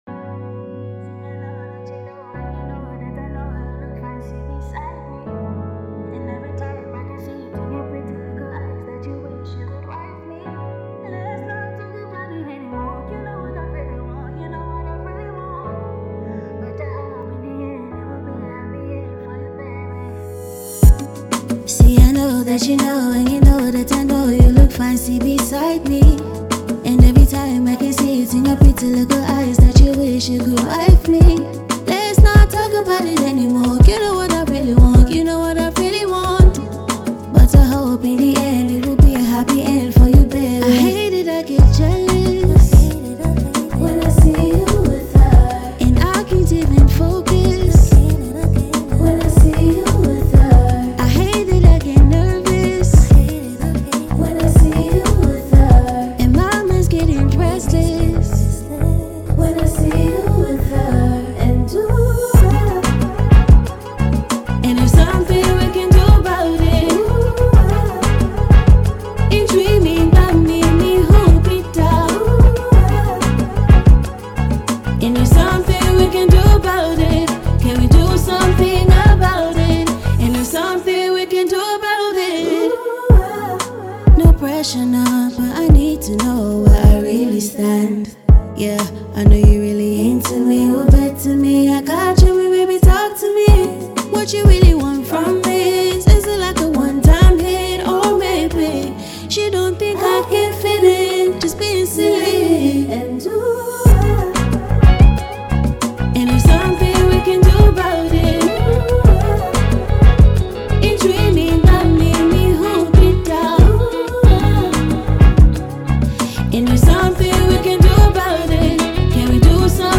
Ghanaian AfroSoul and R&B singer